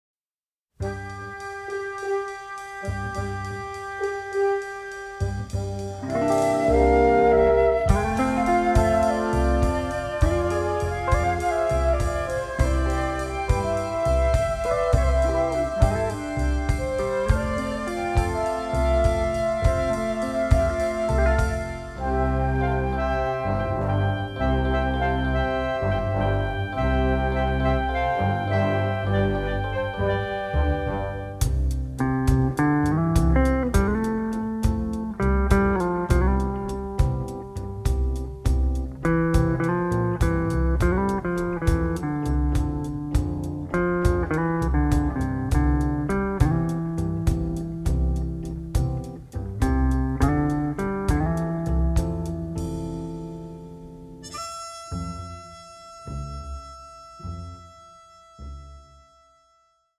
The music is descriptive, romantic and funny
All tracks stereo, except * mono